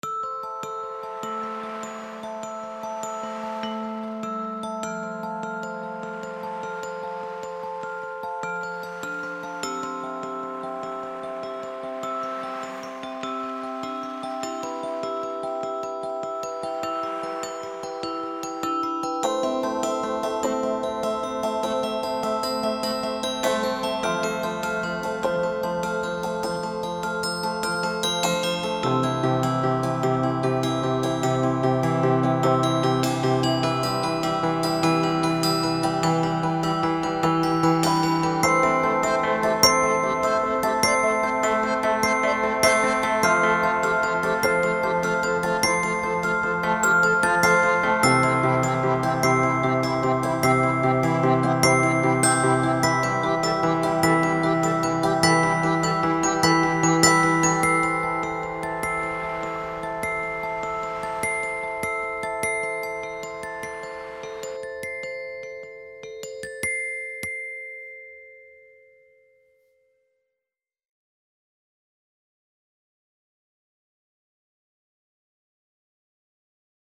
WARM AND MELLOW
NEW REBUILT: A small instrument with a cinematic bell sound.